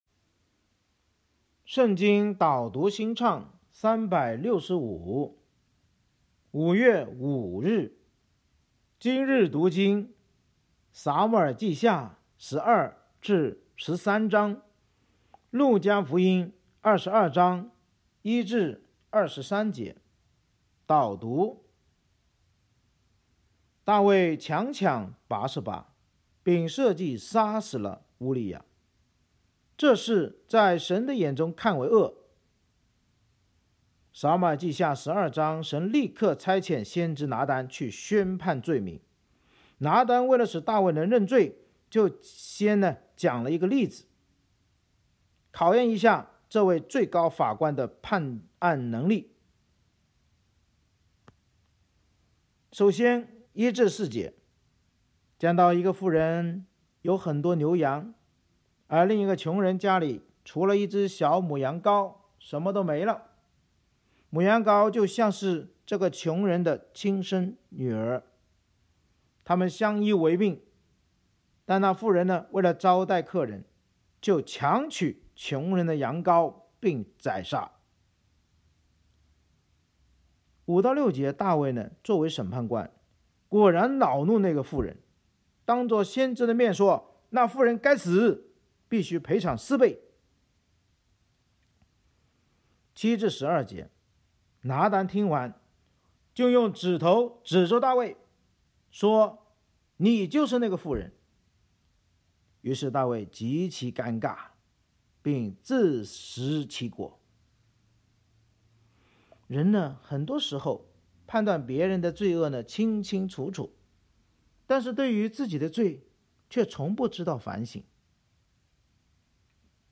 【导读新唱365】朗读5月5日.mp3